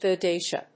音節go・de・ti・a 発音記号・読み方
/goʊdíːʃ(i)ə(米国英語), gəʊdíːʃ(i)ə(英国英語)/